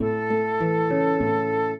flute-harp
minuet7-7.wav